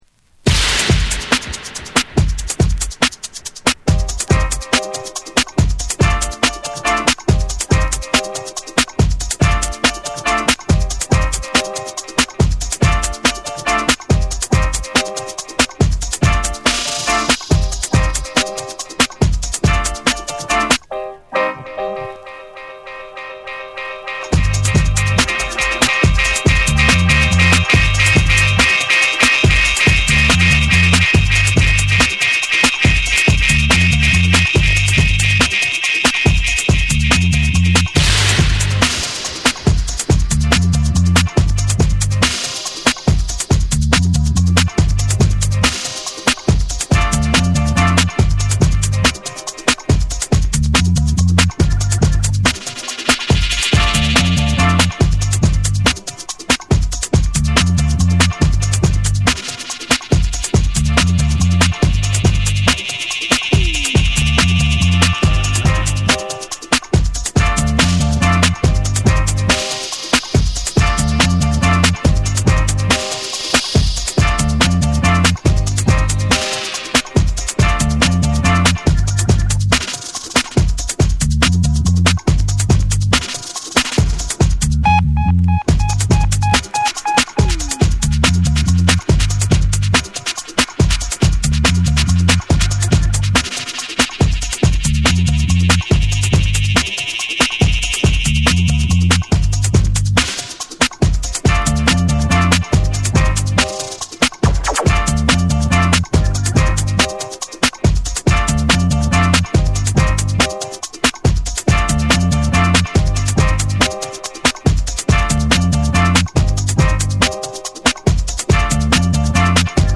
深みのあるベースラインと重量感のあるミックスで、サウンドシステム直系のルーツ・ダブの醍醐味を味わえる一枚。